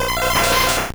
Cri de Noeunoeuf dans Pokémon Or et Argent.